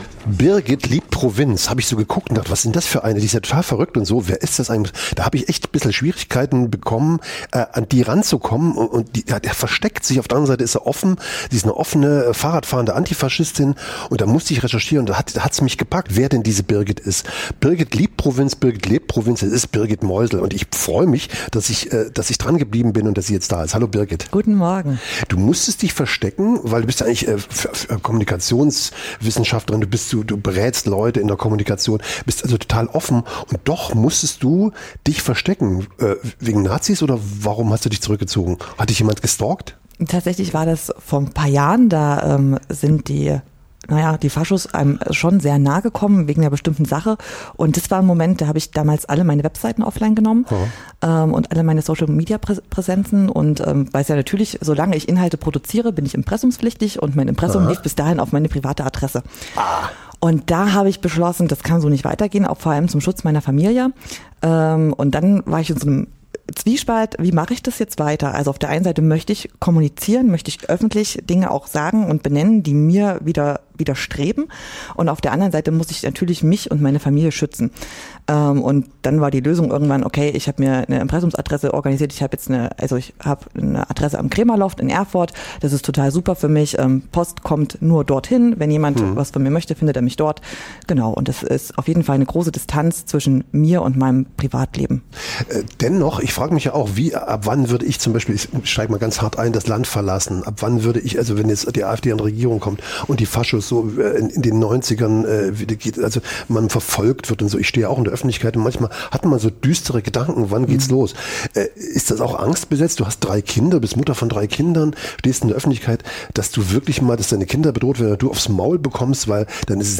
Ein Gespräch über Provinz ohne Folklore, Politik ohne Kitsch � und Widerstand, der nicht auf Likes wartet.